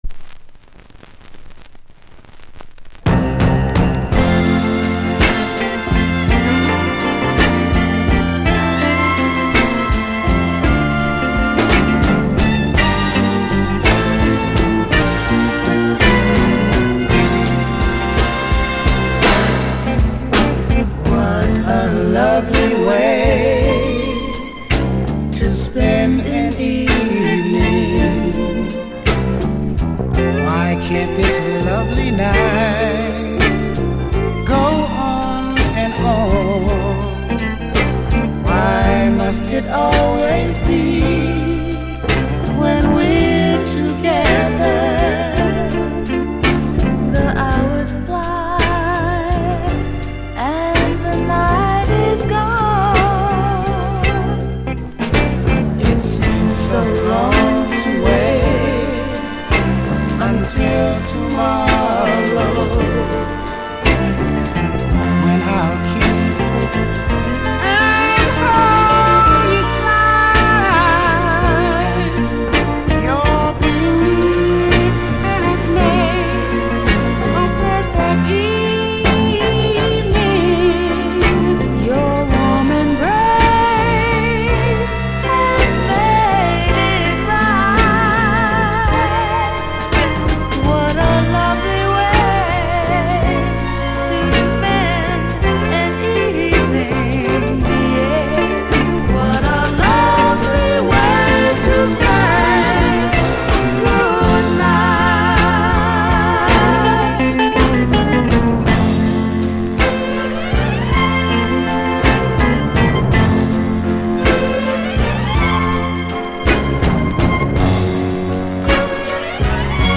7inch / Used / Original
Genre Soul/R&B / Combi
** A面終盤、ラベルシールがズレて音の溝に入ってしまっており、周期的なノイズ入ります。